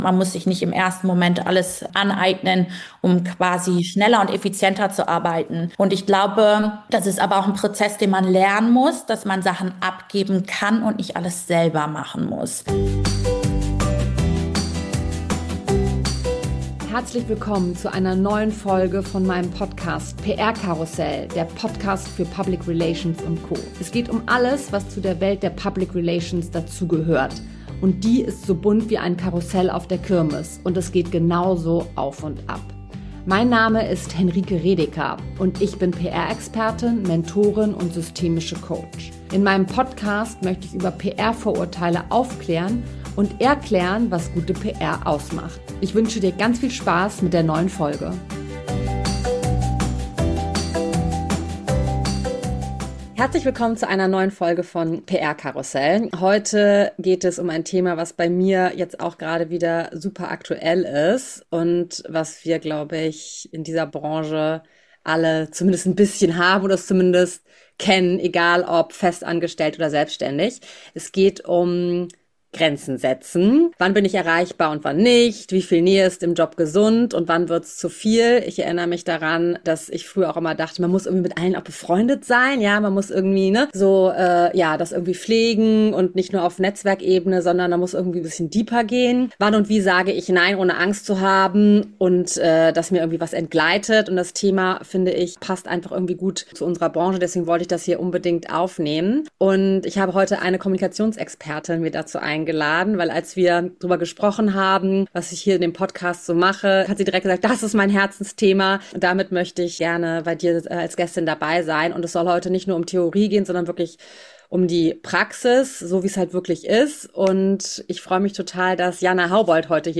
Freu dich auf eine ehrliche Unterhaltung über: Warum Grenzen nicht nur „nett zu haben“ sind, sondern essenziell für langfristigen Erfolg Konkrete Strategien, um sich bewusst Auszeiten zu nehmen Wie Kommunikation im Team helfen kann, Überlastung zu vermeiden Die Rolle von Selbstfürsorge in einer Branche, die oft 24/7 tickt Egal, ob du selbst in der PR arbeitest oder einfach Inspiration für einen gesünderen Umgang mit Arbeit suchst – diese Folge gibt dir neue Denkanstöße und vielleicht auch den Mut, öfter mal „Nein“ zu sagen.